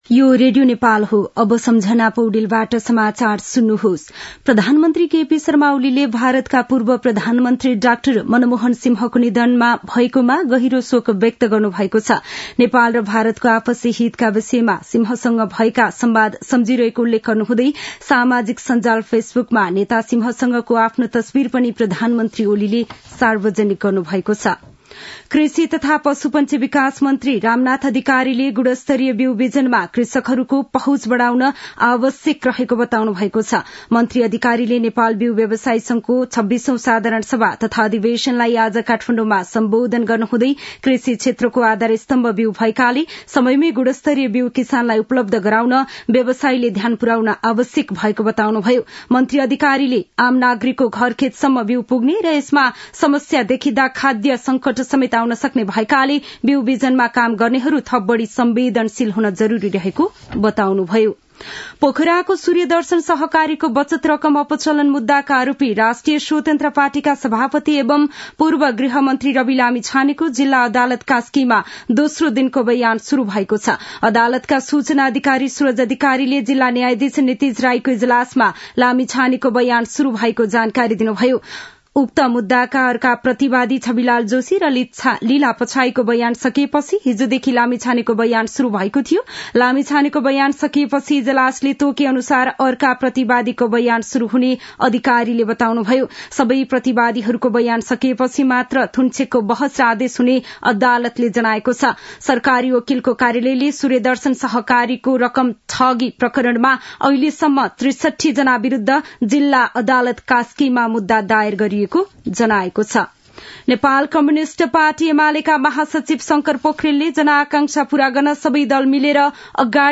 दिउँसो १ बजेको नेपाली समाचार : १३ पुष , २०८१
1-pm-nepali-news-1-20.mp3